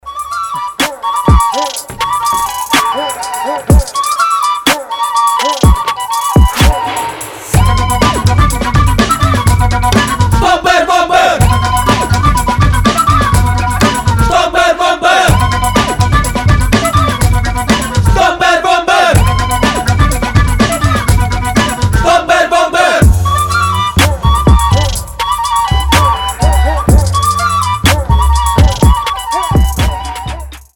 • Качество: 320, Stereo
фолк
дудка
breakbeat
Фольклорный украинский рэпчик